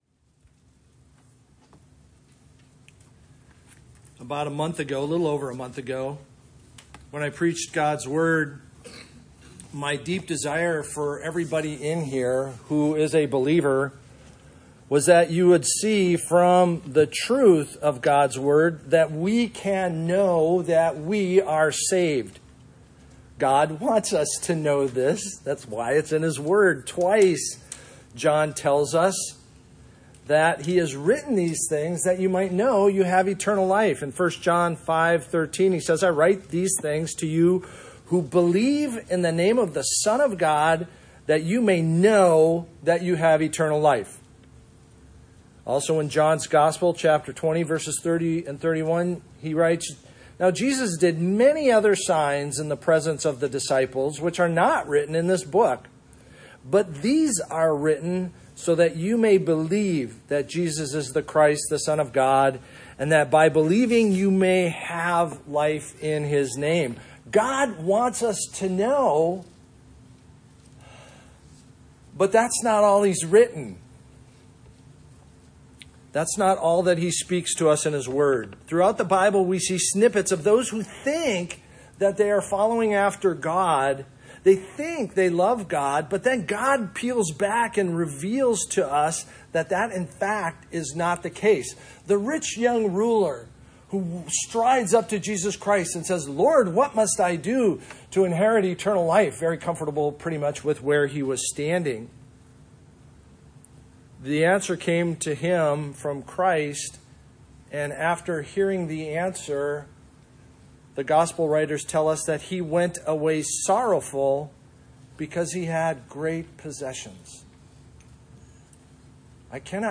Mark 4:1-20 Service Type: Sunday Morning A month ago